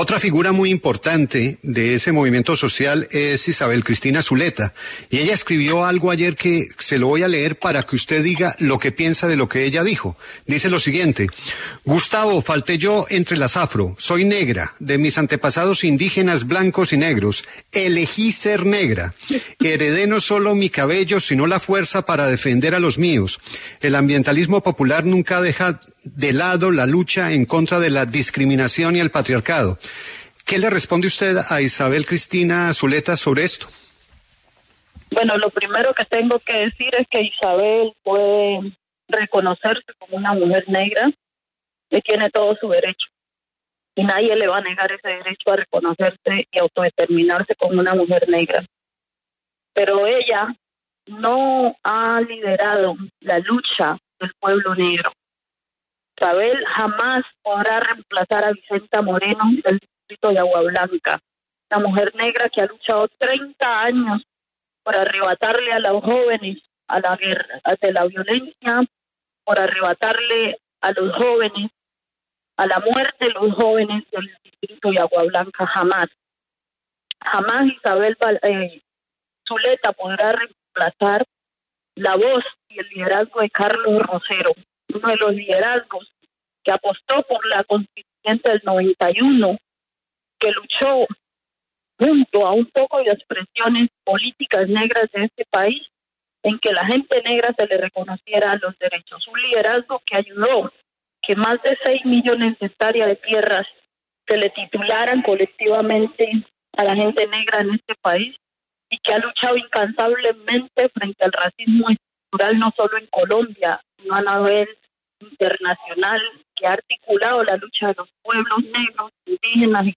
En diálogo con La W, Julio Sánchez Cristo le preguntó a la líder social, Francia Márquez, sobre el trino que ayer publicó la candidata al Senado por el Pacto Histórico, Isabel Cristina Zuleta, en su cuenta de Twitter que dice lo siguiente: